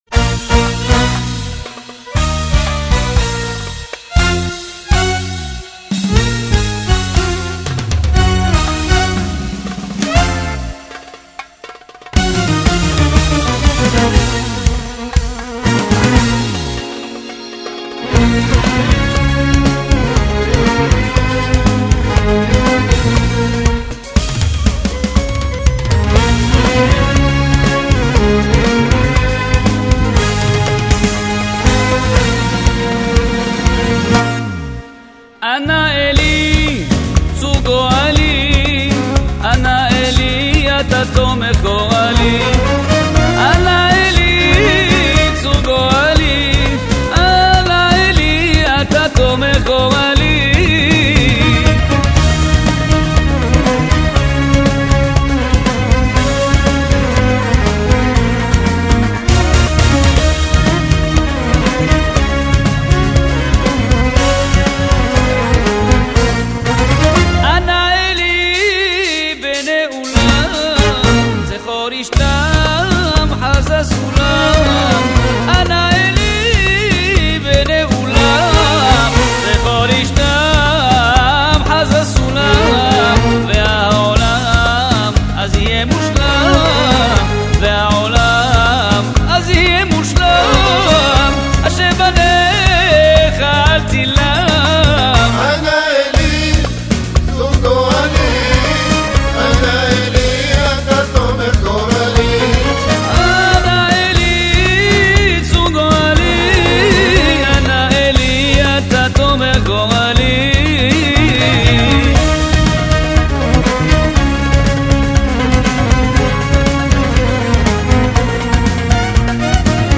הרמוניה אוריינטלית מרהיבה